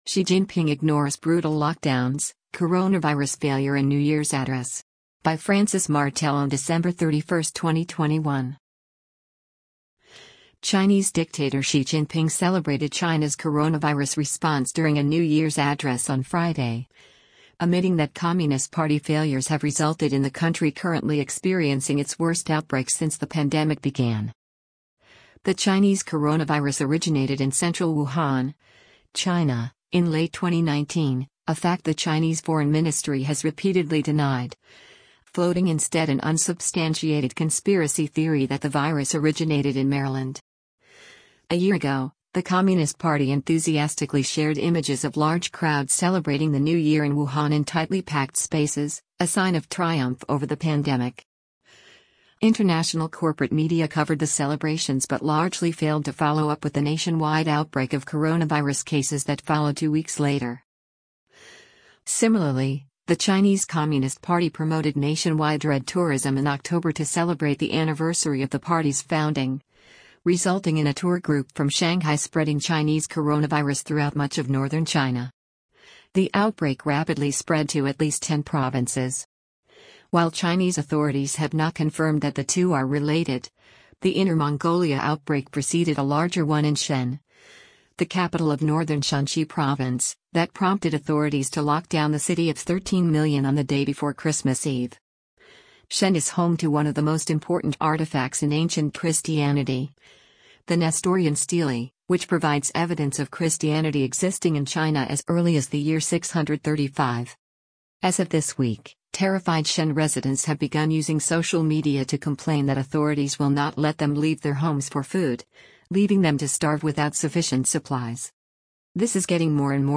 Xi Jinping Ignores Brutal Lockdowns, Coronavirus Failure in New Year's Address